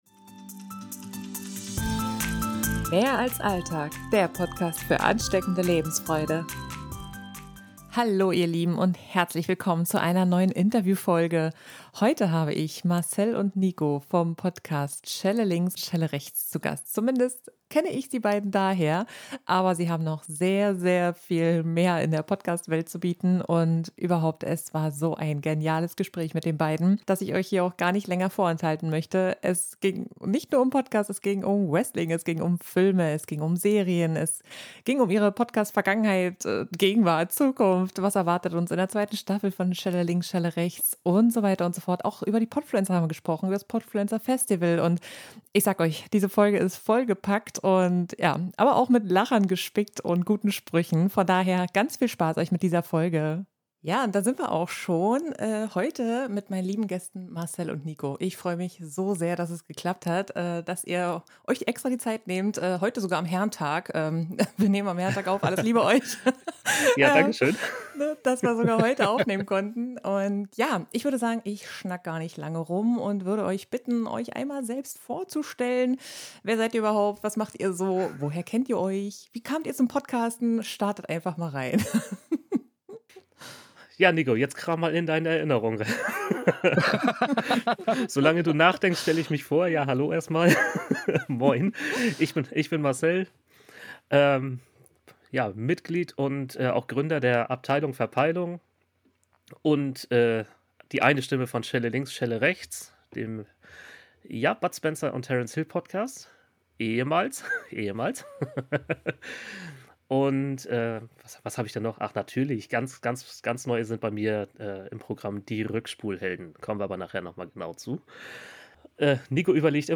#021 - Interview